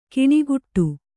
♪ kiṇiguṭṭu